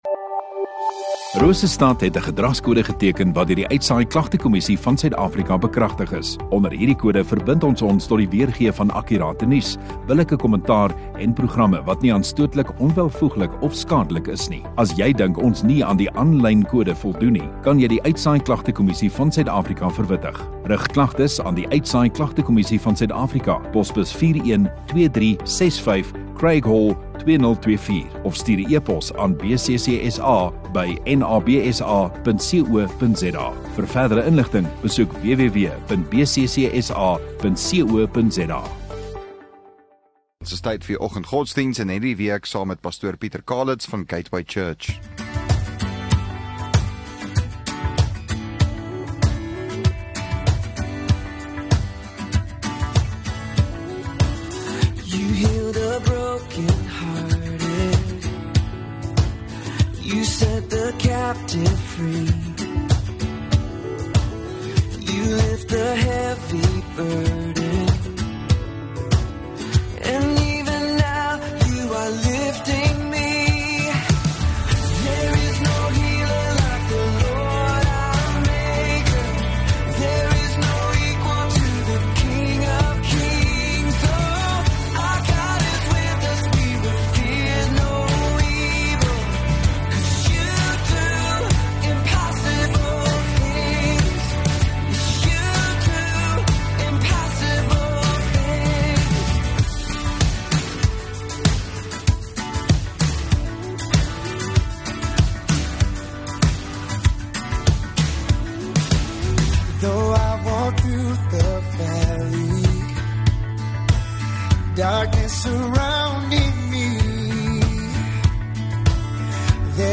3 Apr Donderdag Oggenddiens